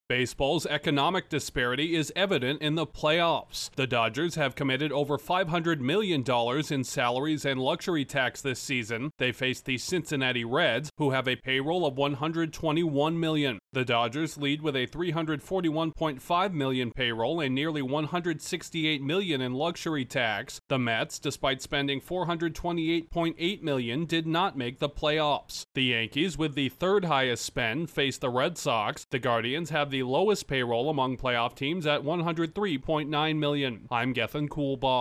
Dueling financial strategies are being put to the test in a baseball postseason featuring six of 12 contenders with payrolls of $200 million or more. Correspondent